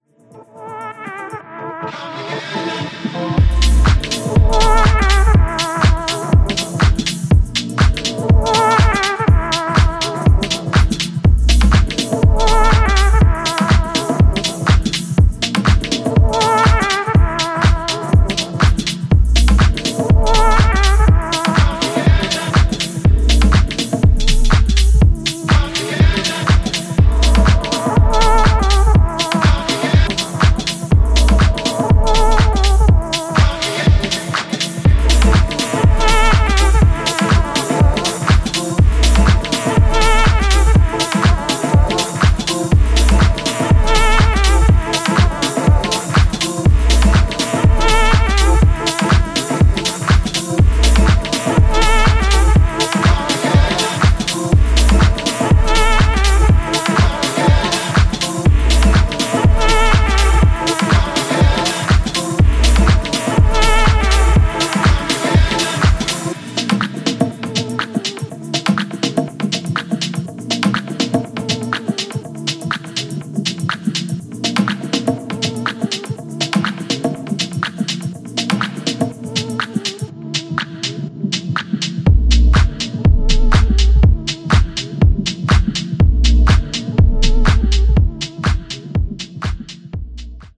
ジャンル(スタイル) DEEP HOUSE